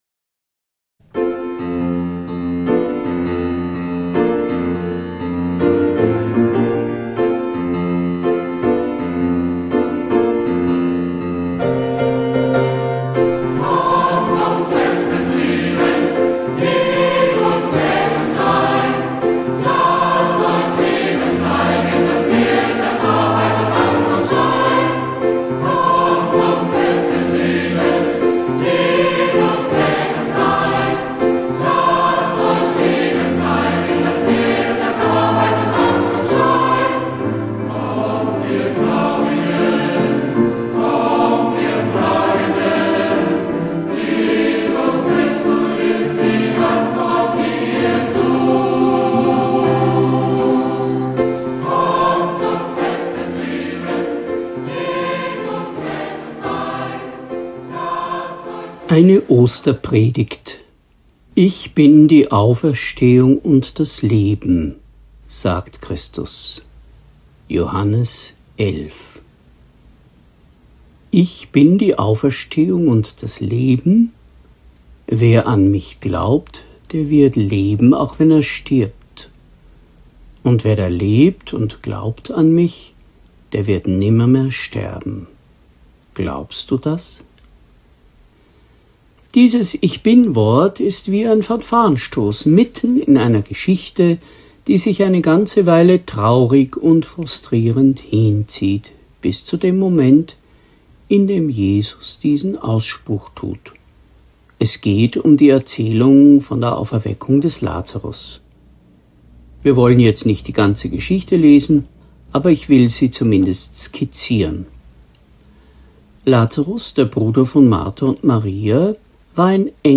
Zum Anhören: Zum Nachlesen: Predigt | NT04 Johannes 11,20-27 Auferstehung und Leben PDF Skript Herunterladen Predigt | NT04 Johannes 11,20-27 Ich bin die Auferstehung augm mM Herunterladen